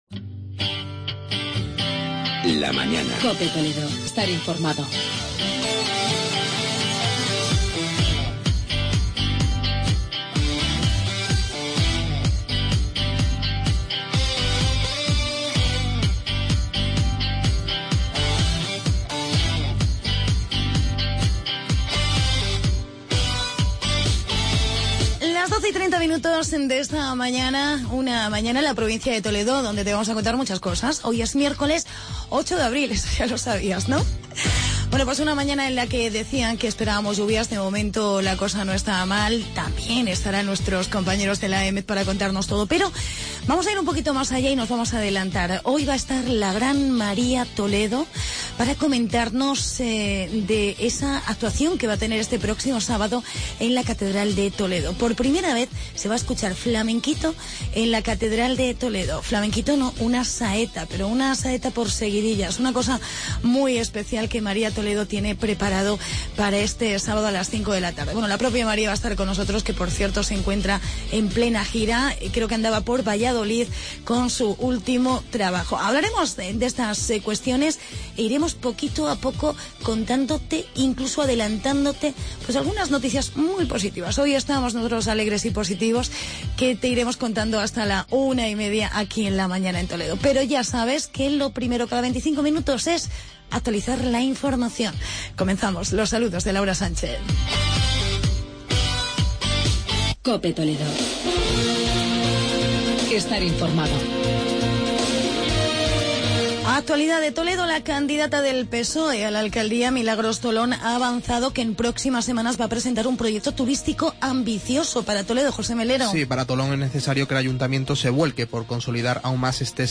Entrevista a la cantante María Toledo y hablamos de la Universidad Francisco de Victoria.